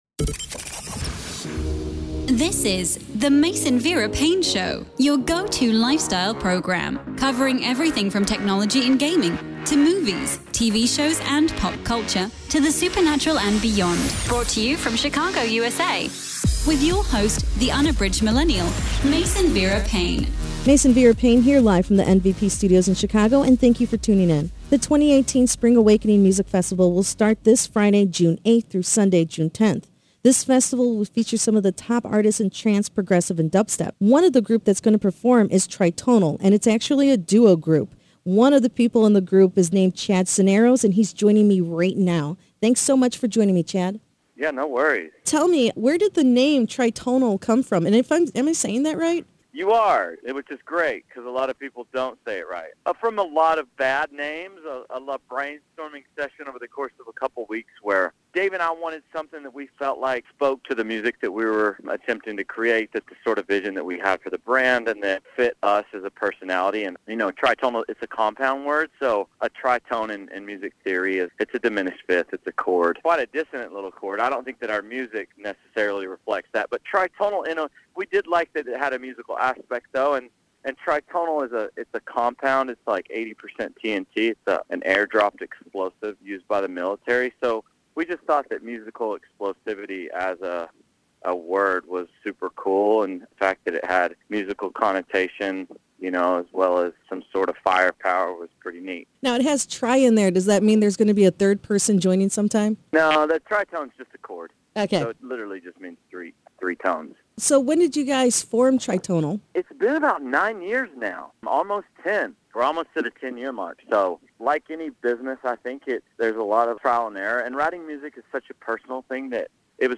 One half of Tritonal, Chad Cisneros joins me today to speak about the duo, how did they decide to form a partnership and their creation process when it comes music.